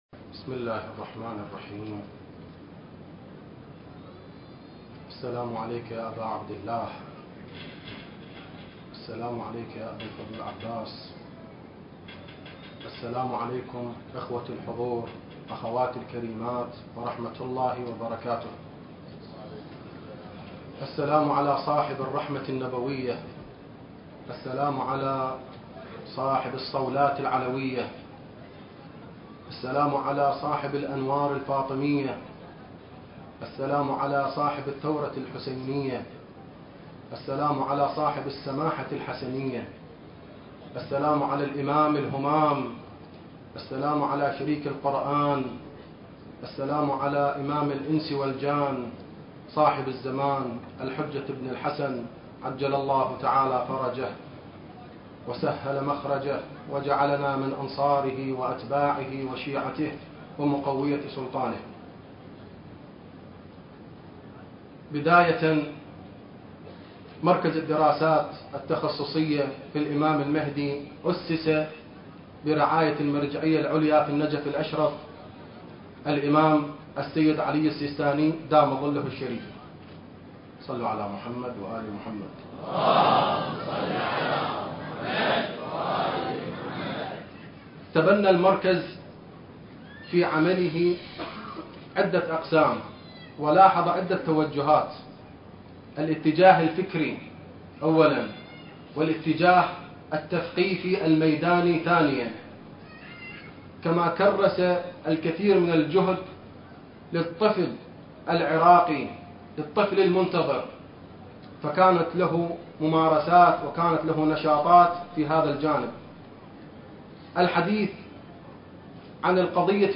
كلمة مركز الدراسات التخصصية في الإمام المهدي (عجّل الله فرجه) في المسابقة المهدوية الثالثة في العتبة الحسينية المقدسة